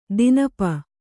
♪ dinapa